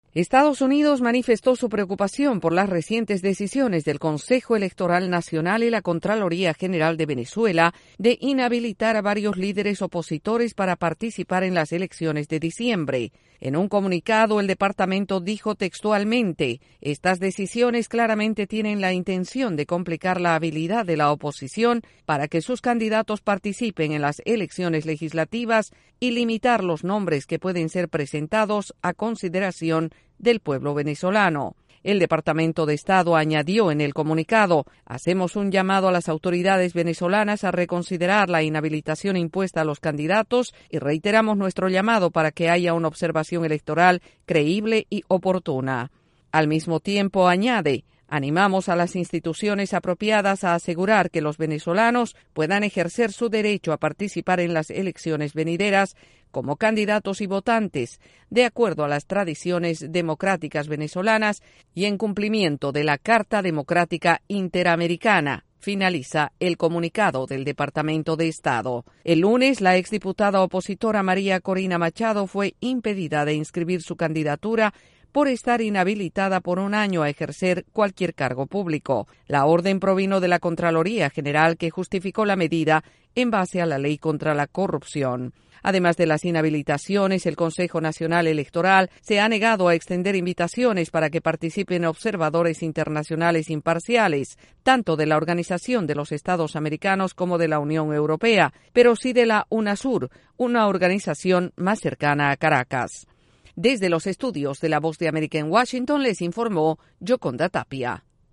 El Departamento de Estado de Estados Unidos expresa preocupación por las inhabilitaciones políticas de líderes opositores en Venezuela. Desde la Voz de América en Washington